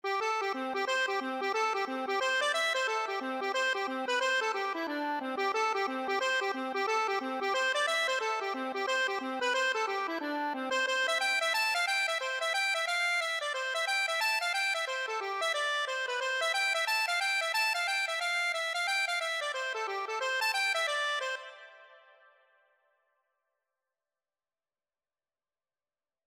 C major (Sounding Pitch) (View more C major Music for Accordion )
4/4 (View more 4/4 Music)
C5-A6
Accordion  (View more Easy Accordion Music)
Traditional (View more Traditional Accordion Music)